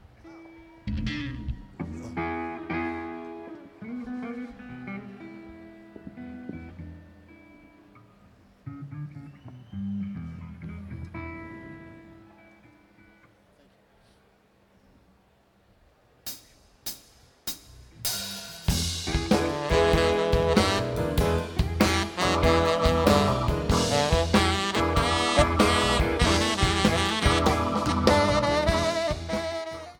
Acoustic
Blues
Reggae